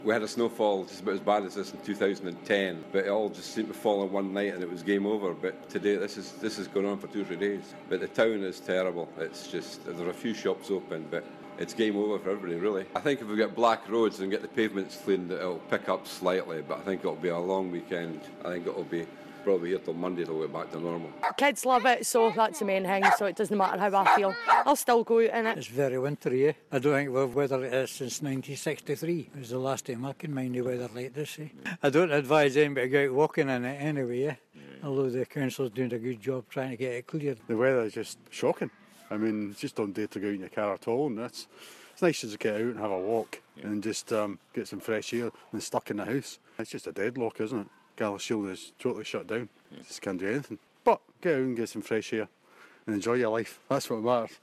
LISTEN: What do you think of the weather? We've been asking those brave enough to venture outside...